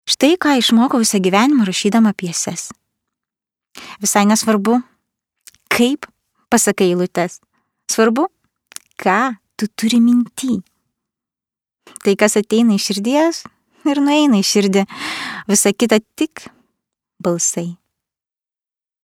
slightly deep, smooth, alluring, safe, passionate and with calming influence voice
Sprechprobe: Industrie (Muttersprache):